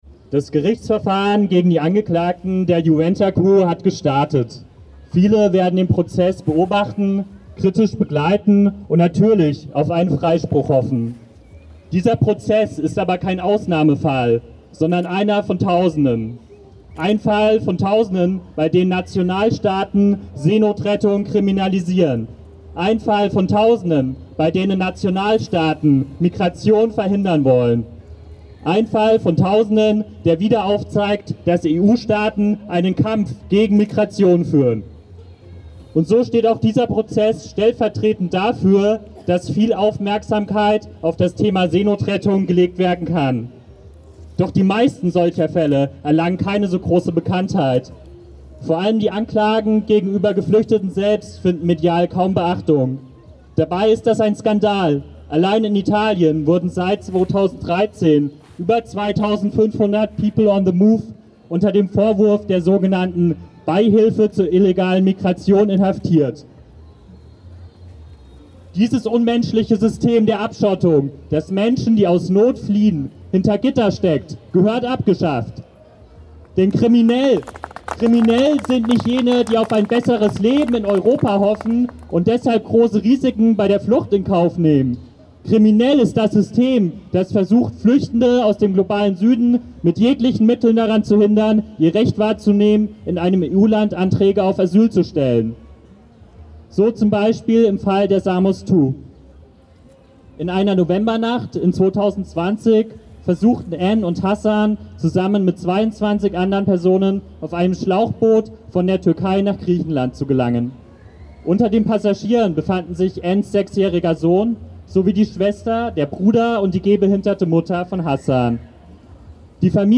Aus diesem Anlass demonstrierten mehrere Dutzend Menschen in Solidarität mit den Angeklagten in Form einer Kundgebung auf dem Stühlinger Kirchplatz in Freiburg.
Rede Seebrücke: